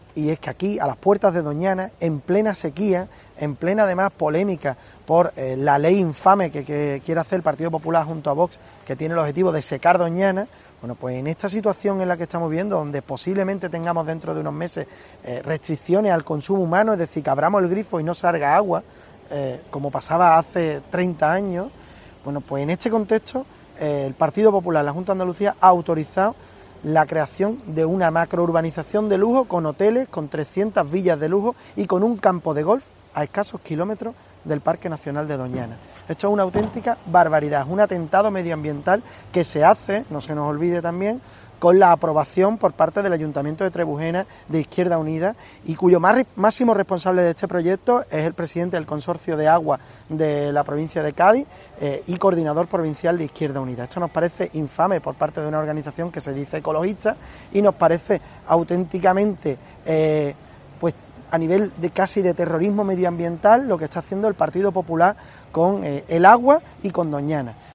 Escucha aquí a Ignacio García, portavoz parlamentario de Adelante Andalucía